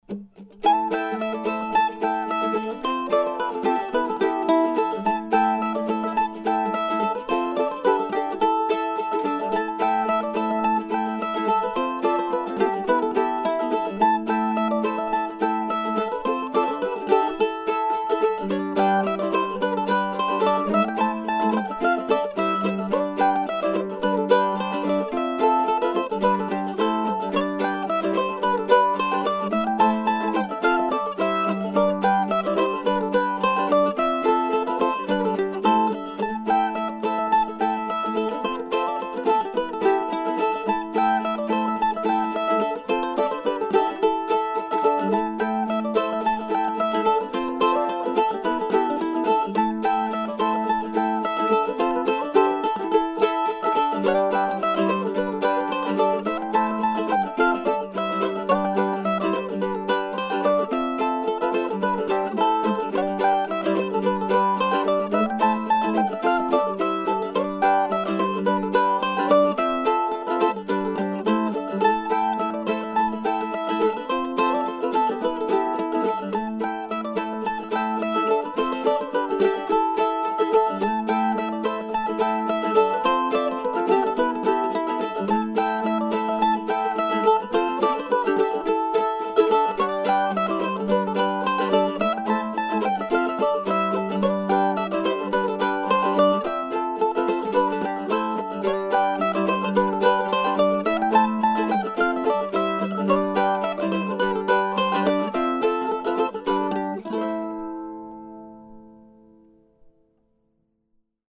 Maybe these are old-time tunes, maybe not.